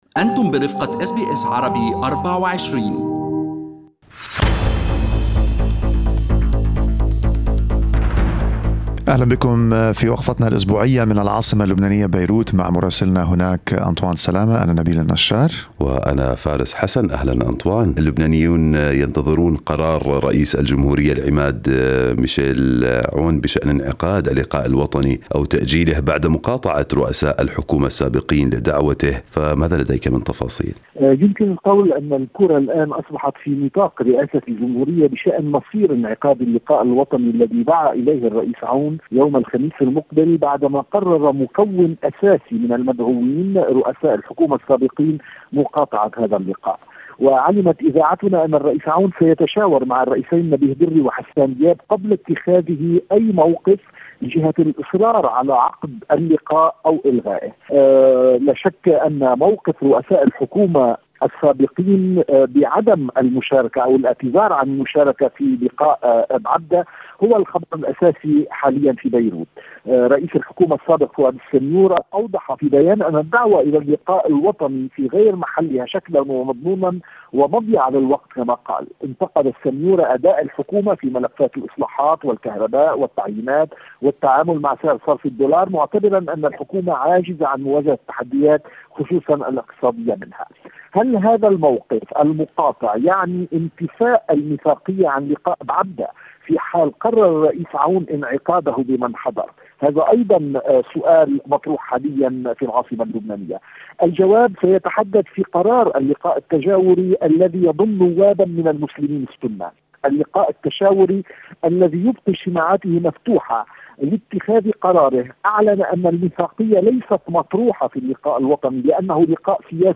من مراسلينا: أخبار لبنان في أسبوع 23/06/2020